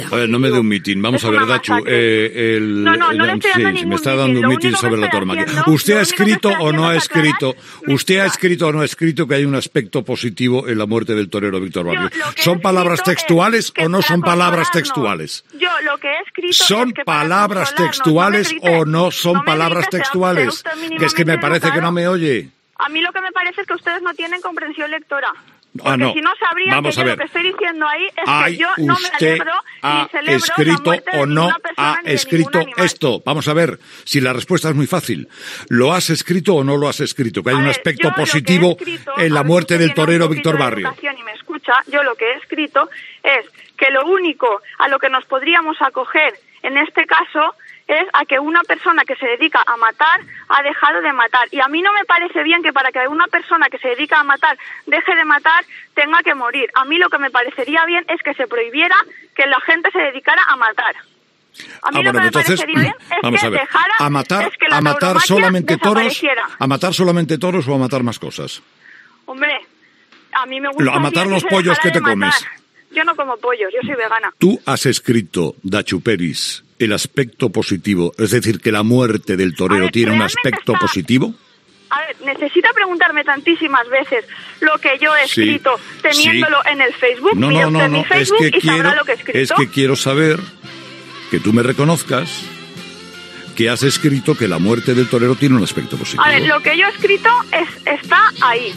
Fragment de l'entrevista a la regidora Datxu Peris, representant de Guanyar Catarroja, després d'escriure un comentari al seu compte de Facebook sobre els 'aspectos positivos' de la mort del torero Víctor Barrio, el 9 de juliol de 2016, a la plaça de braus de Terol, a la Feria del Ángel
Info-entreteniment